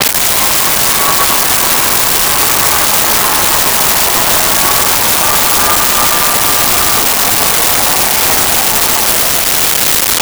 Large Crowd Applause 02
Large Crowd Applause 02.wav